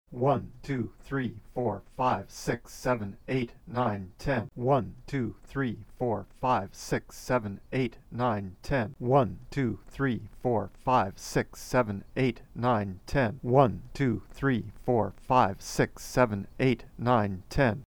Check this sound clip from slide 3 where there are 4 different groups of phase coherence - the sound power & spectrum of each group is the same